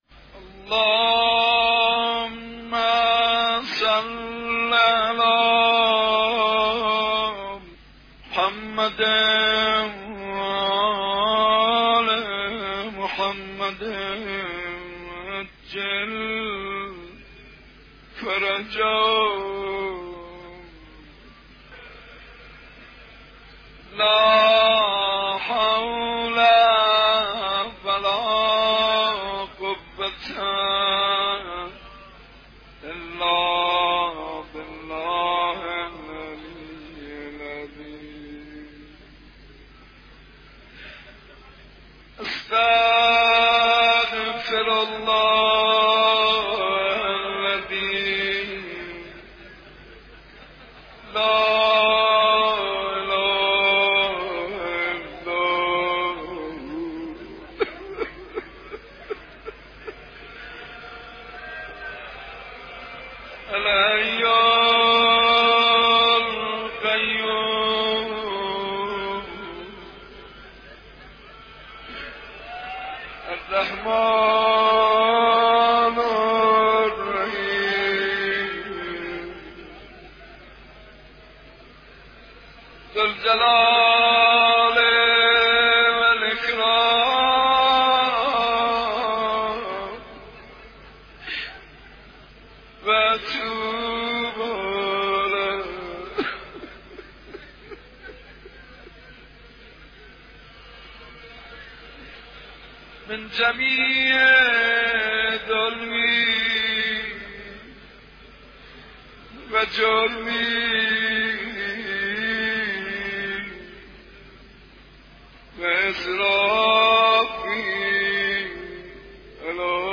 متن دعای عرفه با نوای مداحان و مناجات‌خوان‌های مشهور کشور - تسنیم
در ادامه می‌توانید صوت این دعا را با نوای مناجات‌خوان‌ها و مداحان مشهور گوش کنید و متن دعا را در قالب پی‌دی اف از پیوست همین خبر دریافت کنید.